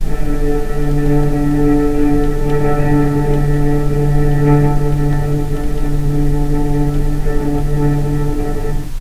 vc-D3-pp.AIF